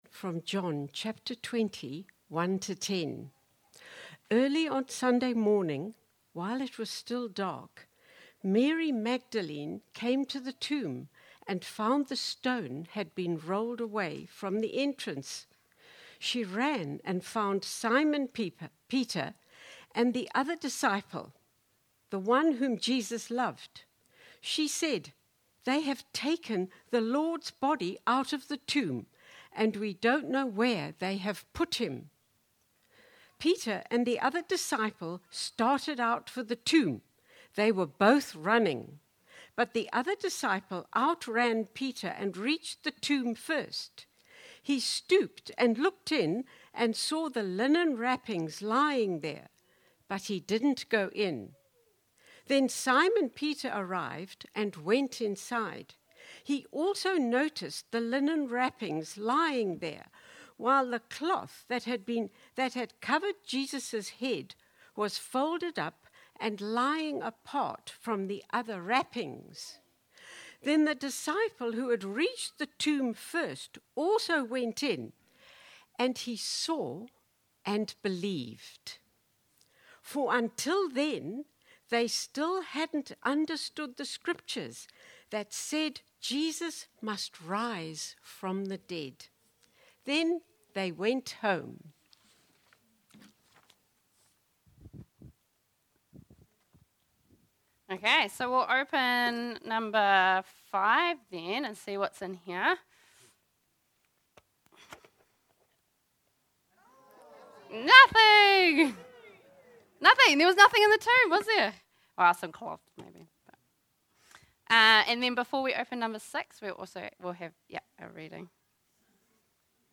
Sermons | All Saints Parish Palmerston North
Easter Sunday